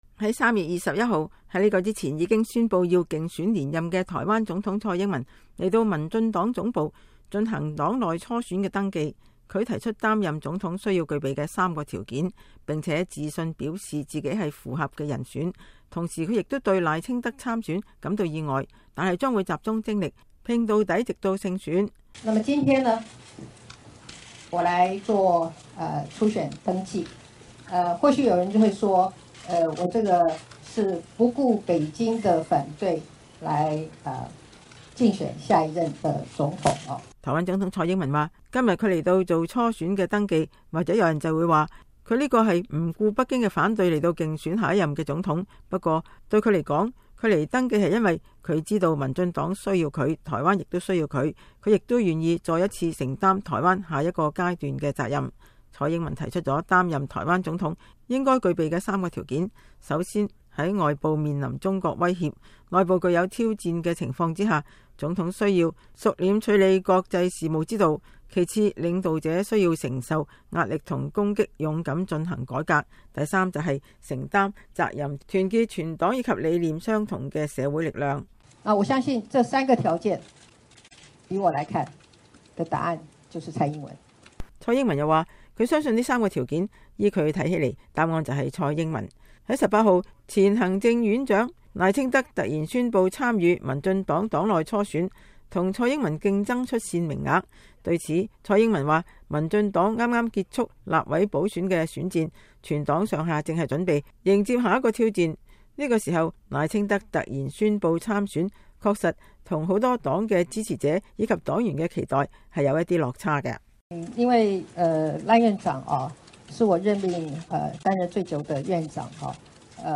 台灣總統蔡英文在乘機出訪太平洋三個邦交國前，來到民進黨總部進行2020總統大選黨內初選的登記，並對賴清德參選一事作出評論。